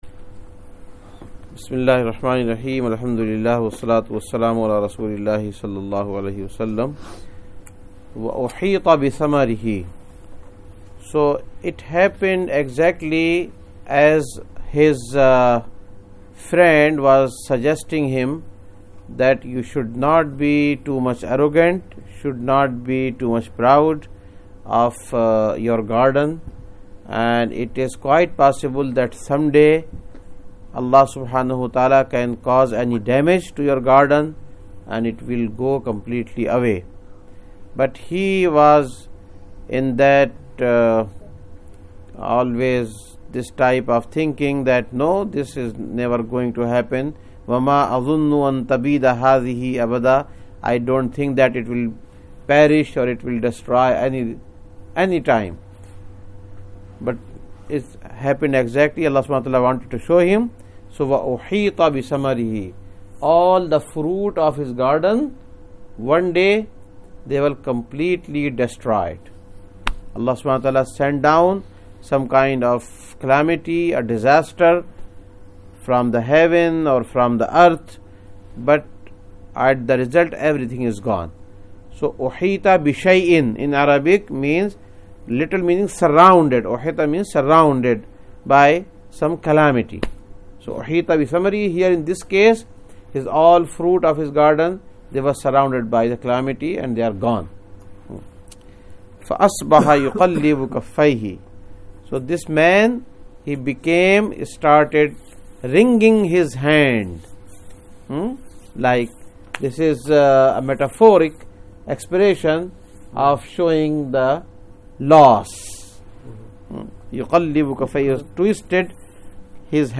#45 Quran Class - AlKahf Ayyat 42-44 Adult Quran Class conducted on 2015-11-15 at Frisco Masjid.